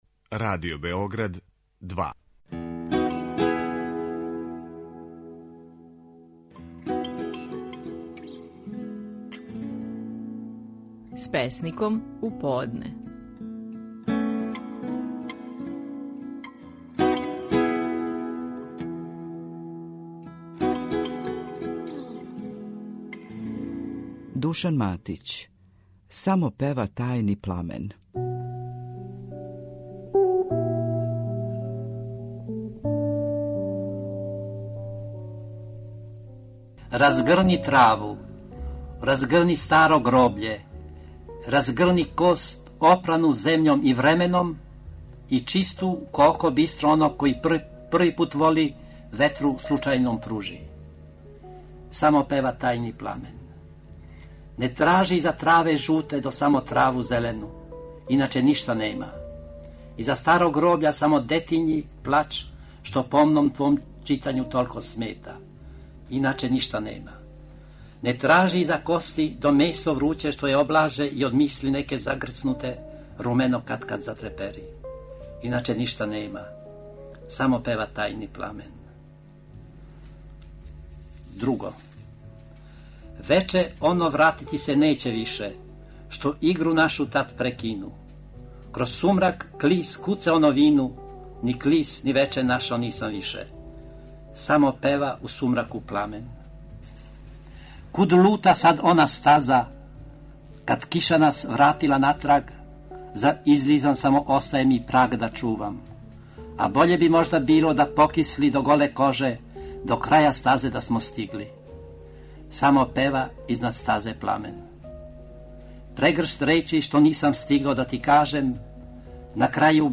Стихови наших најпознатијих песника, у интерпретацији аутора.
Душан Матић говори своју песму „Само пева тајни пламен".